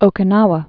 (ōkĭ-näwə, -nou-)